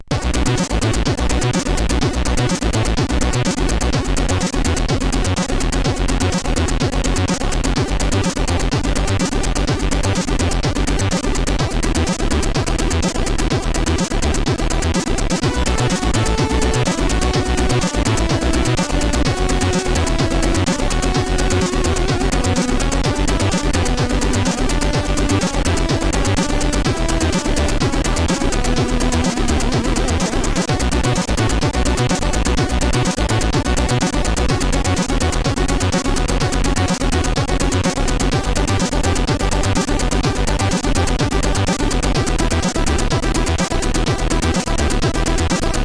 SID 칩에서 생성된 음악의 예
칩의 세 개 채널 때문에 화음은 종종 아르페지오로 연주된다.